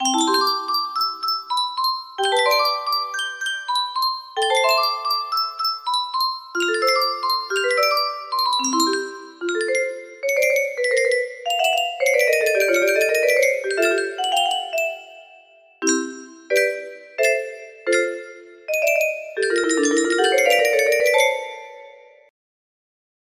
Clone of Unknown Artist - Untitled music box melody
Grand Illusions 30 (F scale)